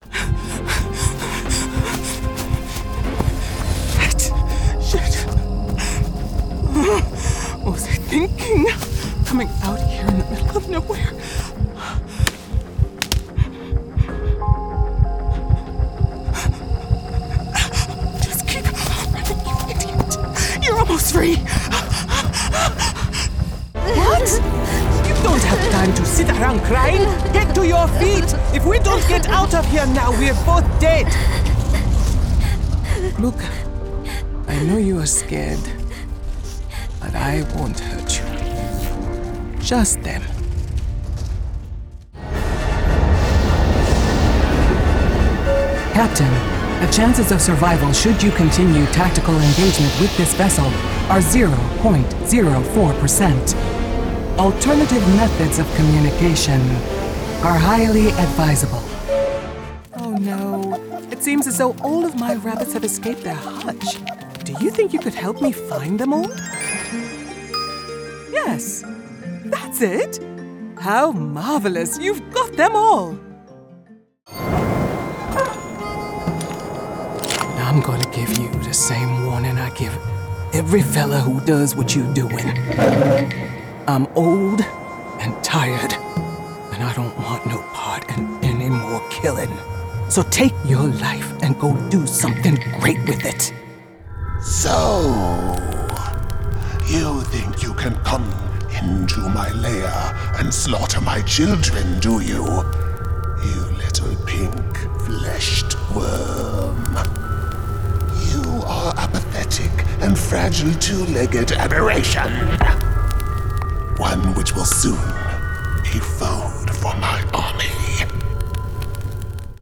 Young Adult, Adult, Mature Adult
Has Own Studio
standard us | natural
GAMING 🎮